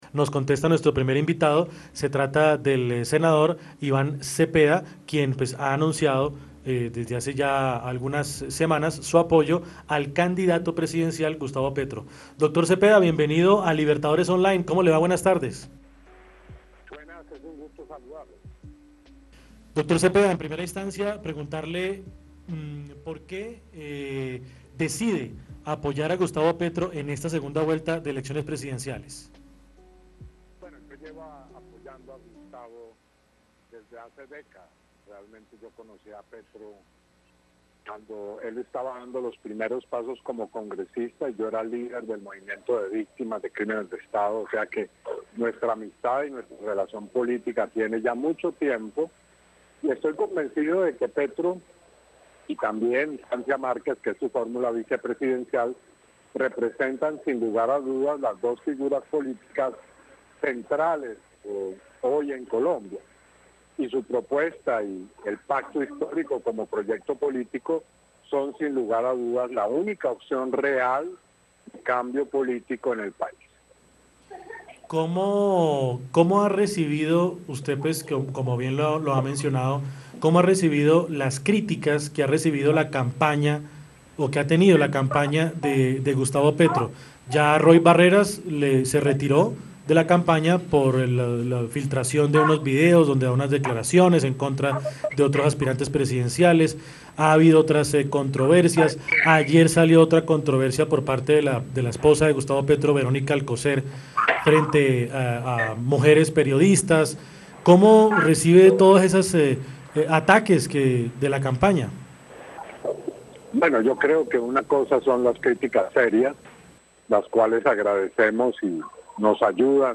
El congresista del Polo Democrático Iván Cepeda estuvo en diálogo con el programa de radio Voces que Miran de la emisora Libertadores Online.
Entrevista-a-Ivan-Cepeda.mp3